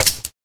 TriggerNoAmmo.wav